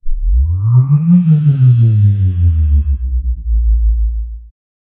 a-high-energy-sci-fi-weap-kfdptxmm.wav